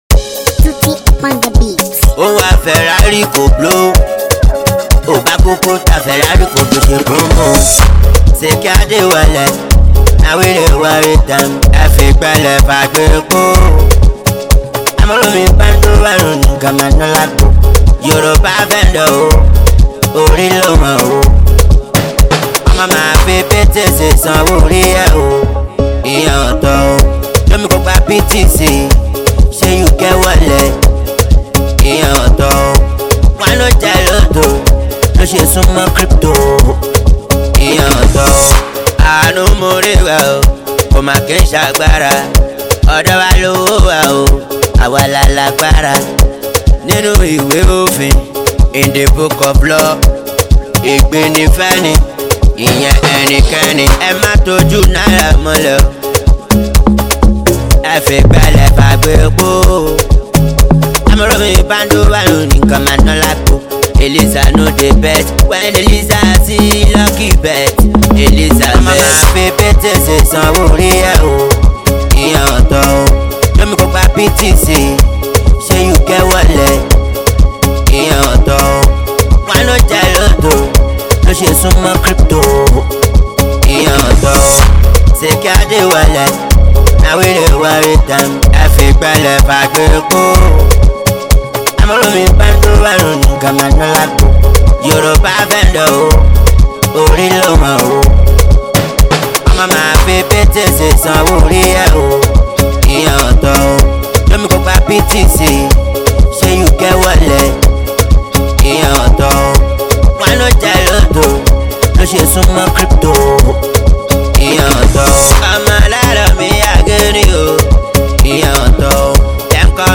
Insanely talented Nigerian street-hop singer